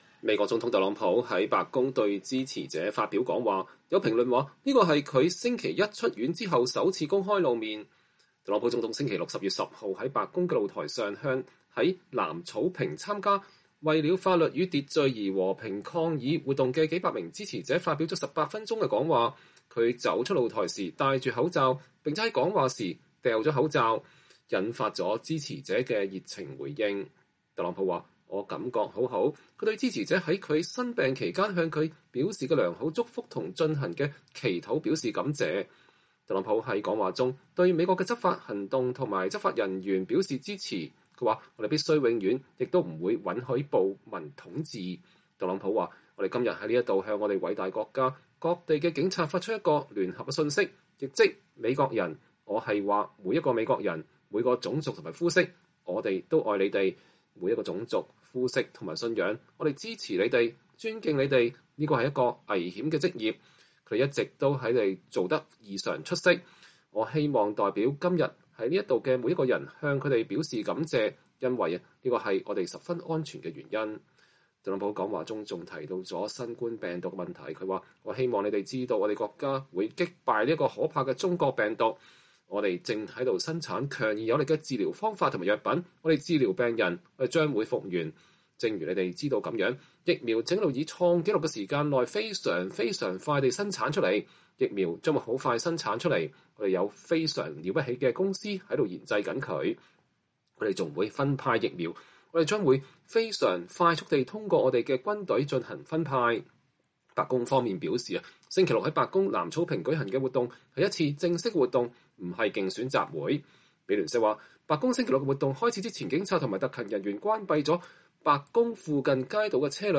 美國總統特朗普10月10日在白宮對支持者發表講話。
特朗普總統星期六（10月10日）在白宮陽台上向在南草坪參加“為了法律與秩序而和平抗議”活動的數百名支持者發表了18分鐘的講話。